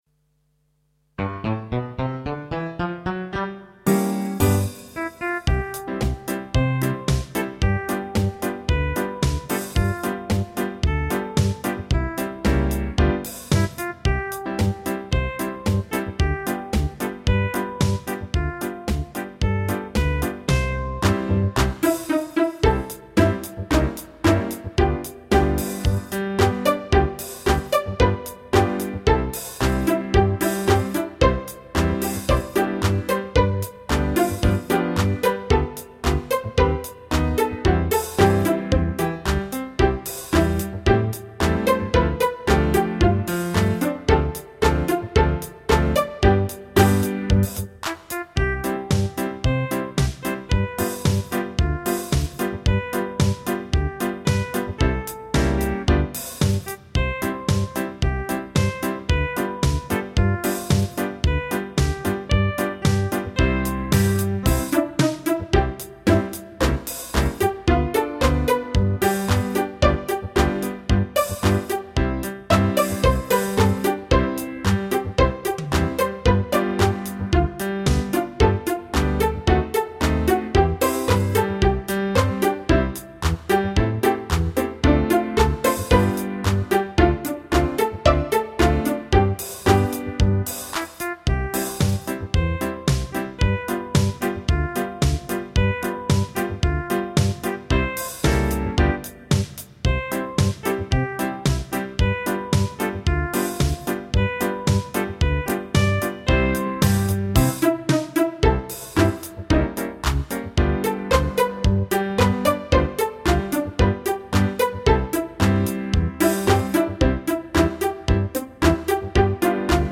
Version instrumentale :